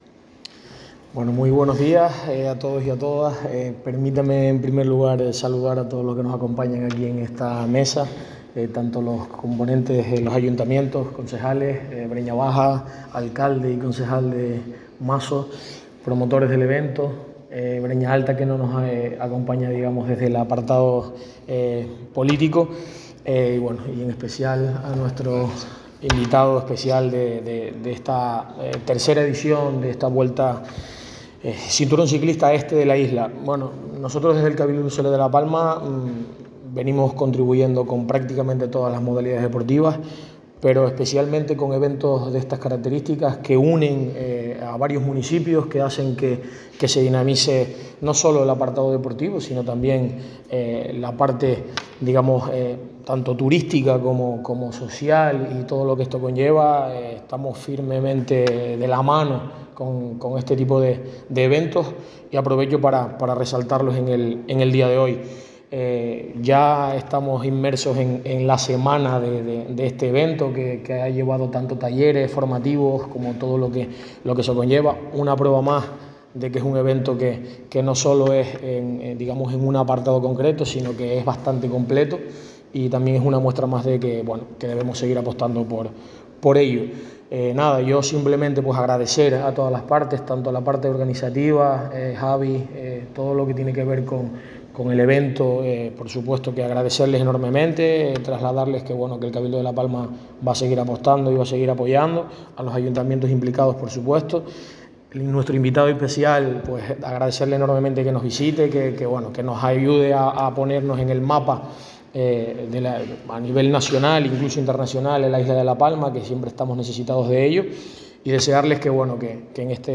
Rueda-de-prensa-Cinturón-Ciclista-Este.mp3